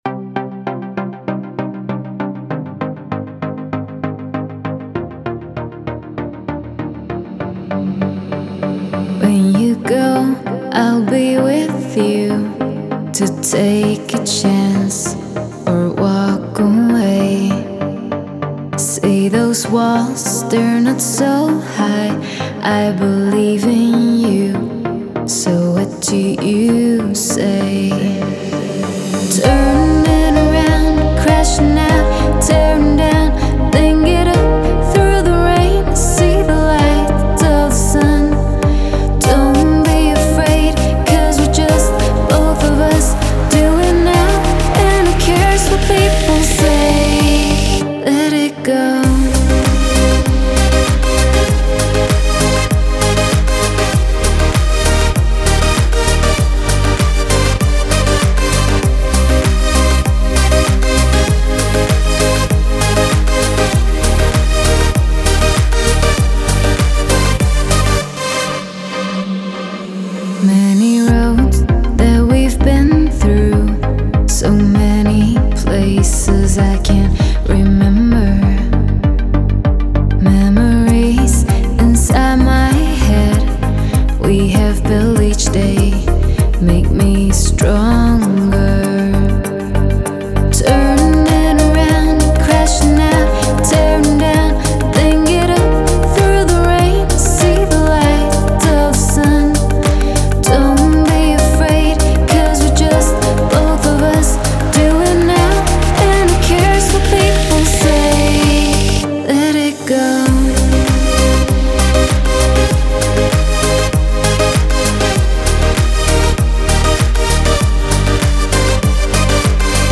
это яркая и атмосферная композиция в жанре электроники